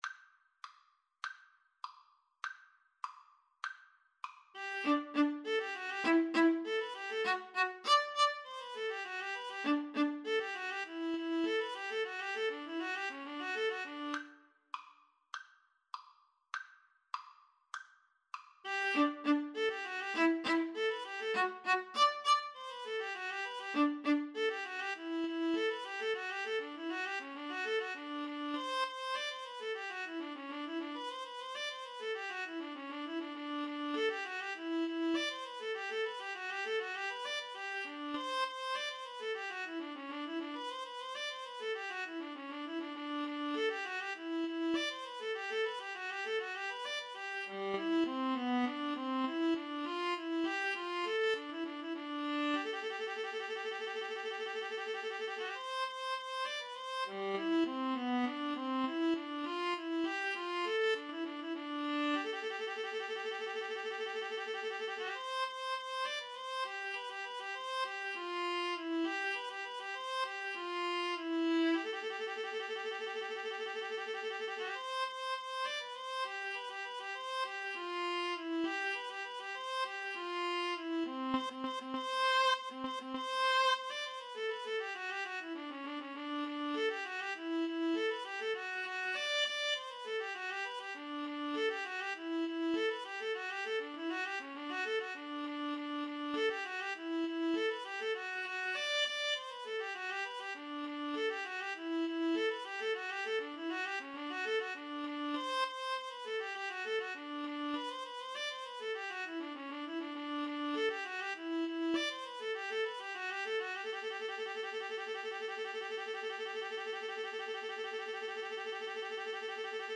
2/4 (View more 2/4 Music)